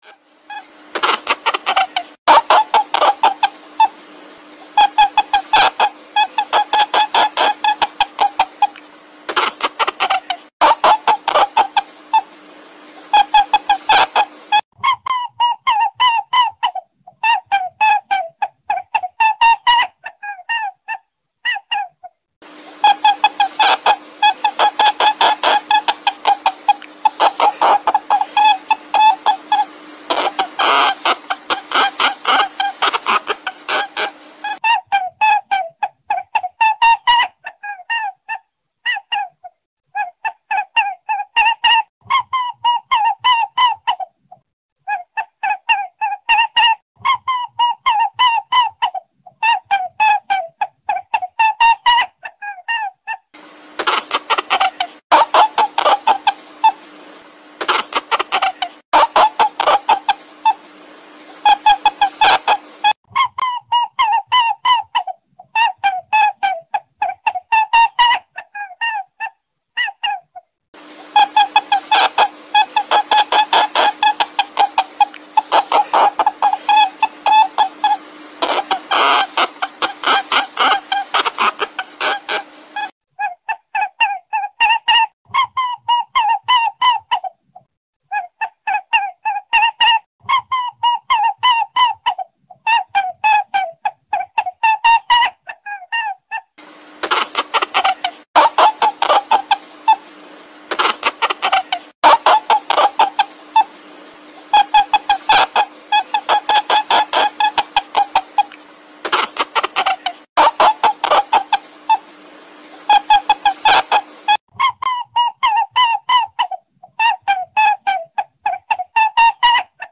เสียงกระต่ายป่ามันร้องหาคู่ mp3
หมวดหมู่: เสียงสัตว์ป่า
คำอธิบาย: คุณสามารถดาวน์โหลดเสียงกระต่ายป่าร้องเพลง MP3 ได้ สำหรับต่อเสียงชัดมาสเตอร์ 100% และได้ผลแน่นอน 100% เสียงกระต่ายป่ามันร้องหาคู่ เข้าไว เสียงดี ได้ผลเกินคาด และเสียงต่อกระต่ายป่า